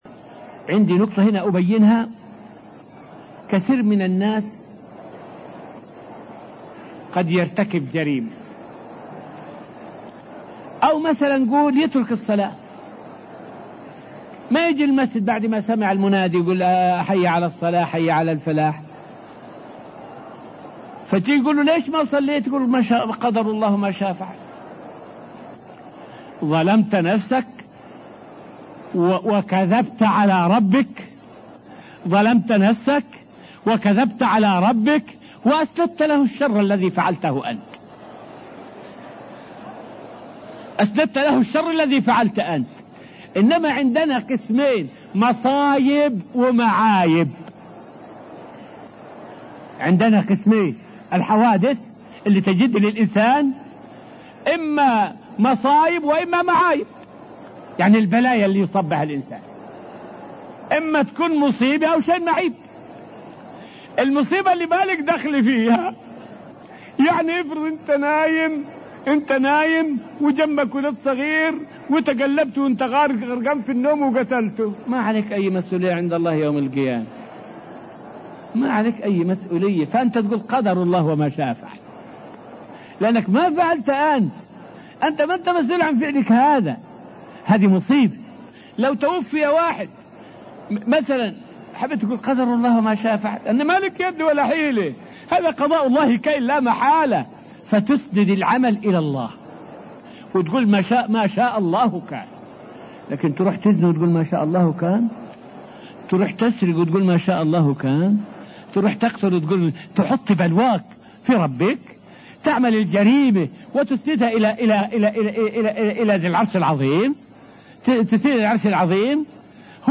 فائدة من الدرس السابع عشر من دروس تفسير سورة البقرة والتي ألقيت في المسجد النبوي الشريف حول لا يجوز نسبة الذنوب لمشيئة الله.